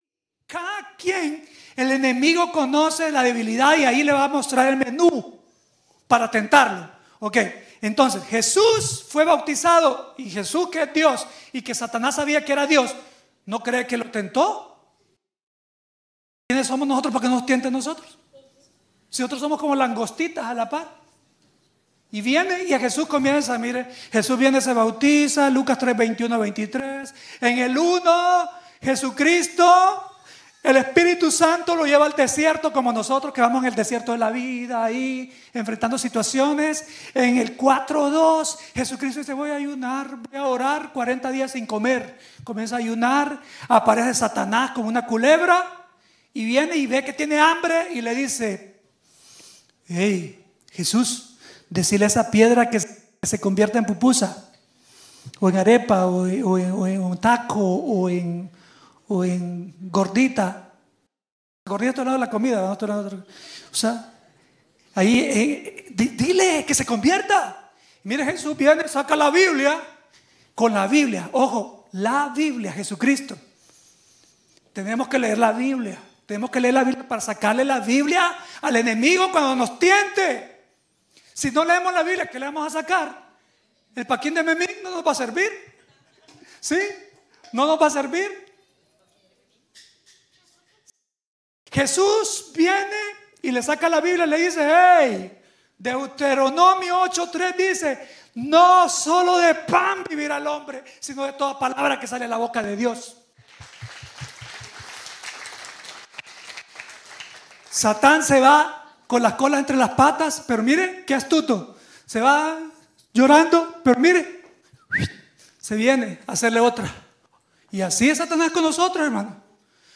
predica hoy sobre el tercer enemigo del hijo de Dios